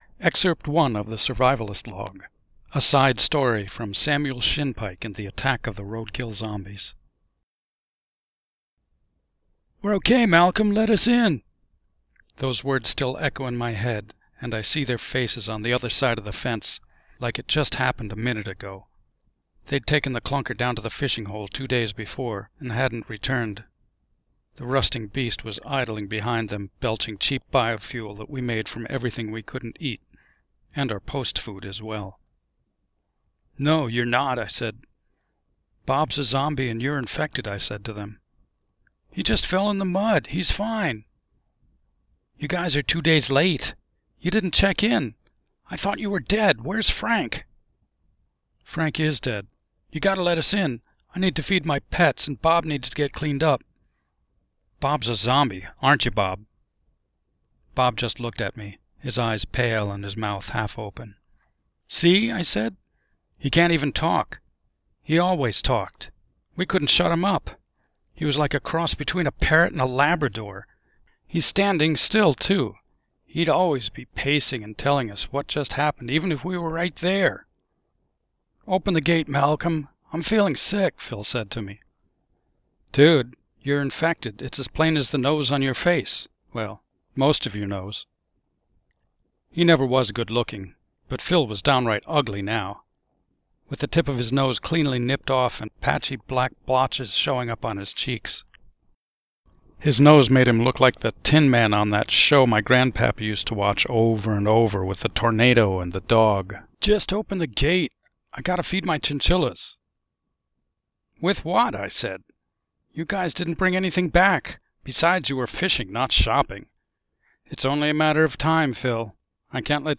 The equipment is sub-par but the mp3 is passable, I will need to move to a room where the clatter of pot lids and the rattle of the dishwasher won’t interrupt me.
As I’ve read through it I’ve found a few time gaffs in the story and corrected them on the fly.